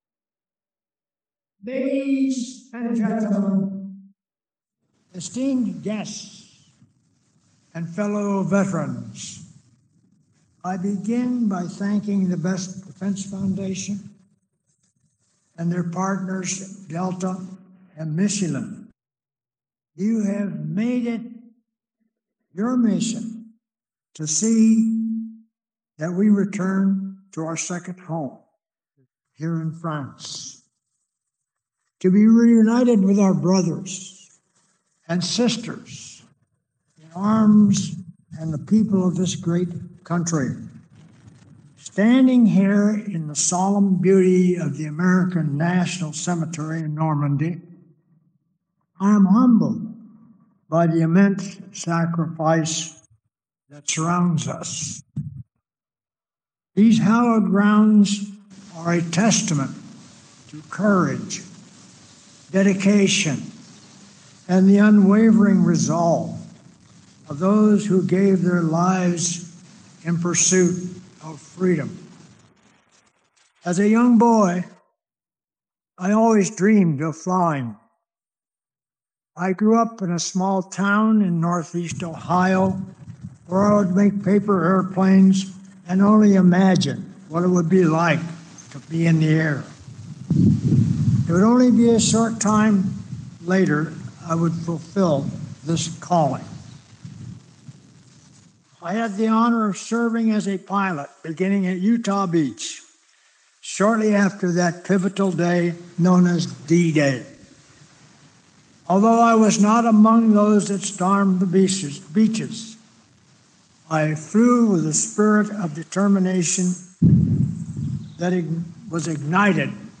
Address at the 81st D-Day Landings Anniversary at Normandy American Cemetery
delivered 6 June 2025, Colleville-sur-Mer, France
AR-XE-NR mp3 of Address
Audio Note: AR-XE-NR = Extermeme Enhancement + AI Noise Reduction